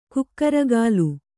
♪ kukkaragālu